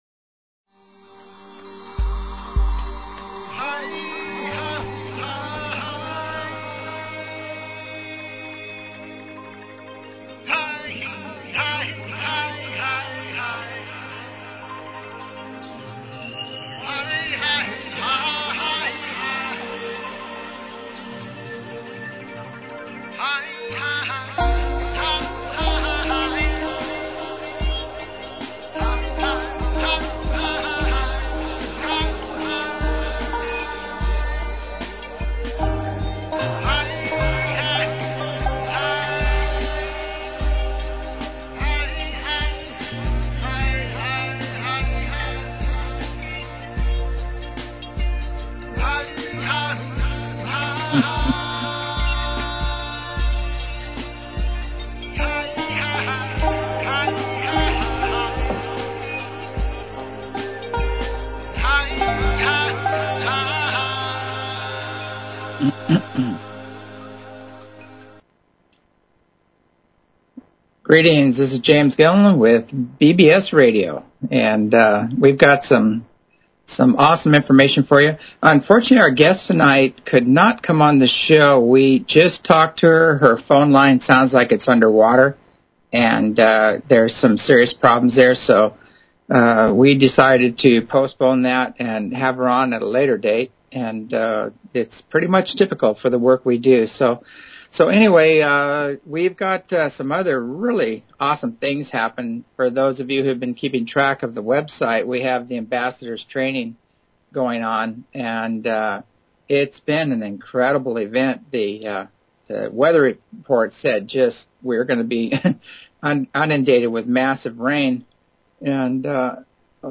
Talk Show Episode, Audio Podcast, As_You_Wish_Talk_Radio and Courtesy of BBS Radio on , show guests , about , categorized as
Broadcast Live From ECETI RANCH